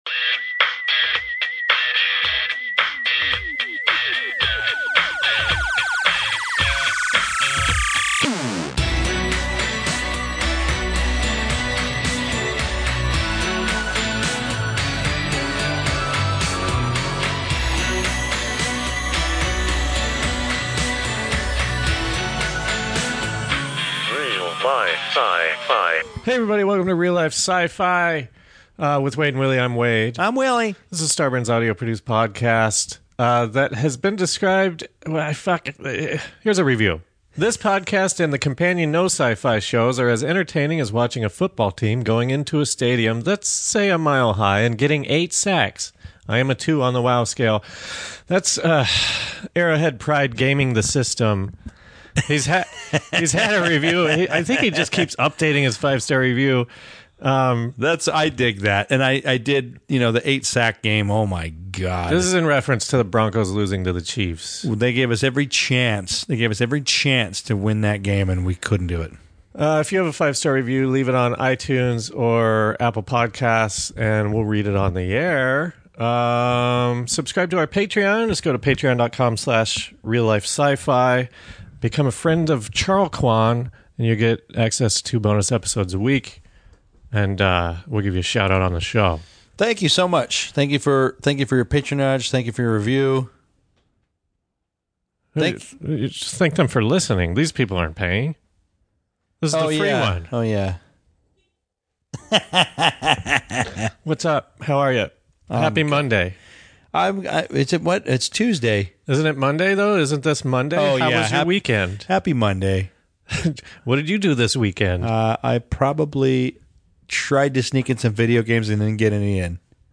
Our friend talks about his DMT experience and we try to talk about the third eye. Any connections? 3 guys drinking beer can figure anything out, am I right?